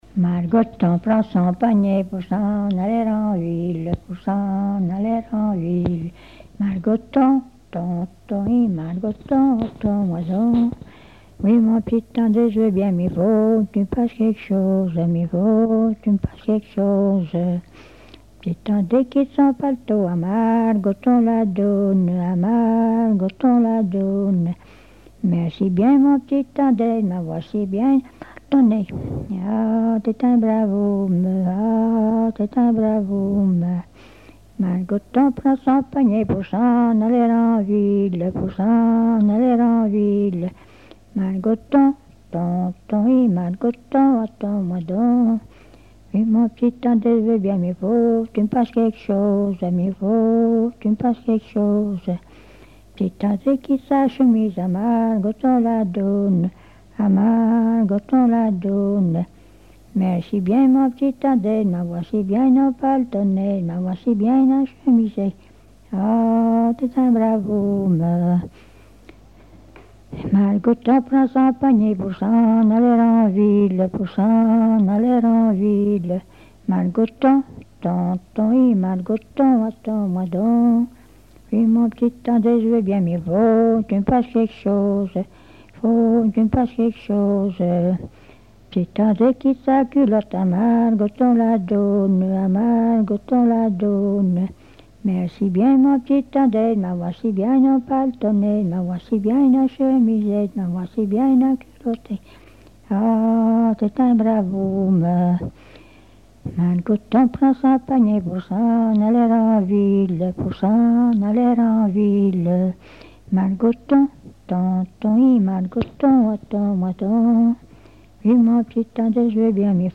Saint-Jean-de-Monts
Chansons traditionnelles
Pièce musicale inédite